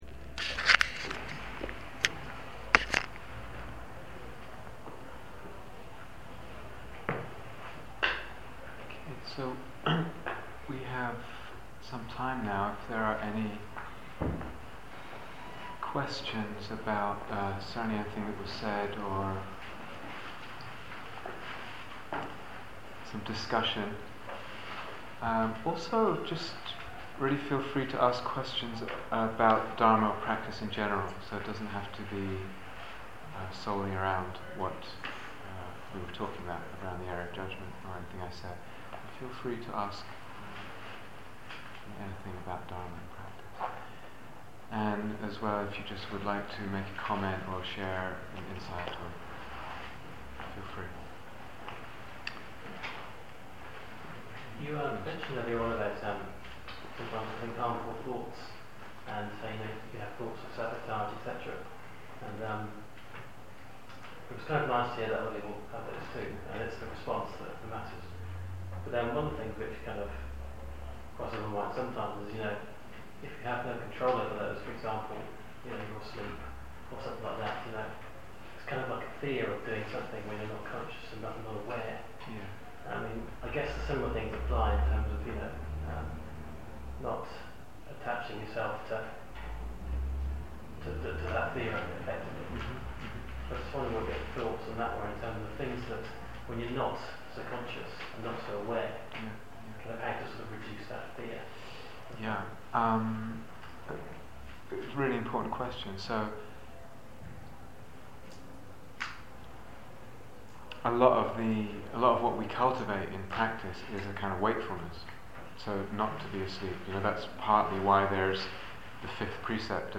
Questions and Answers
Cambridge Day Retreats 2007